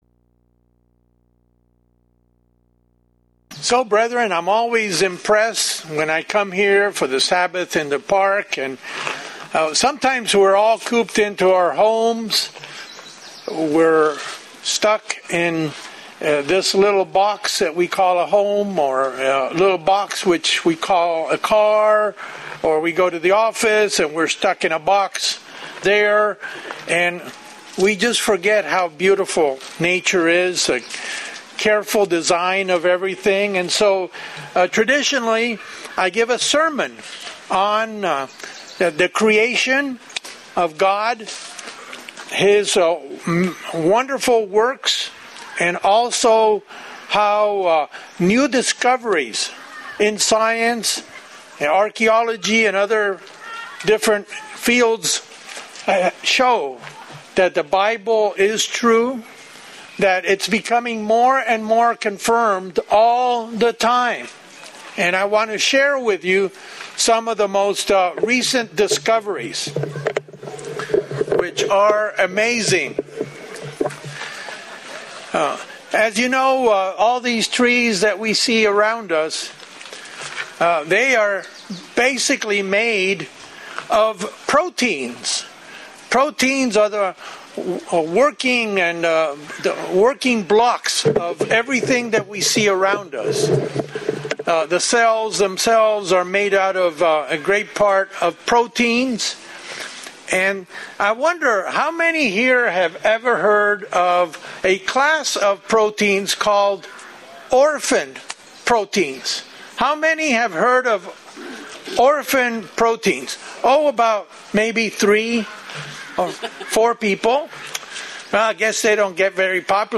In this message given during our Sabbath in the Park, we will see how recent discoveries lend proof to God's existence through genetic research and archaeology. Also, world events continue to fulfill prophecy according to God's Will.